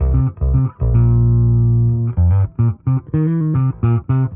Index of /musicradar/dusty-funk-samples/Bass/110bpm
DF_PegBass_110-C.wav